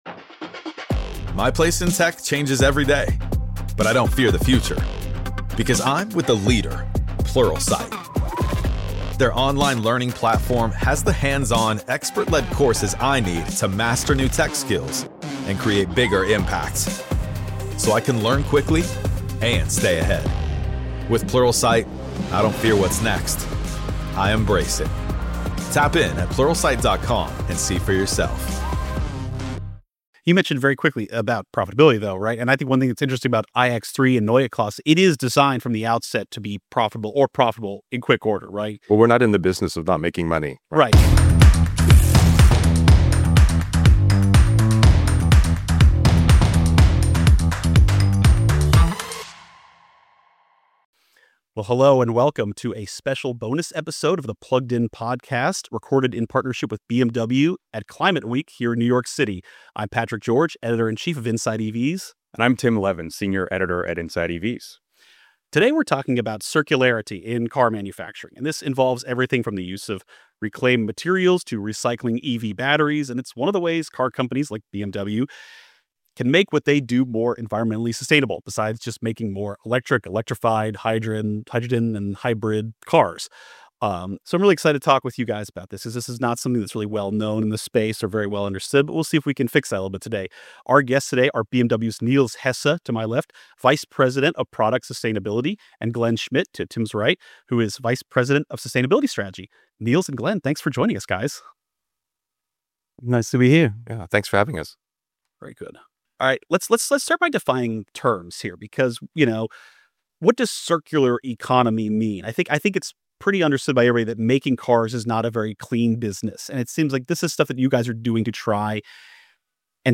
recorded at Climate Week NYC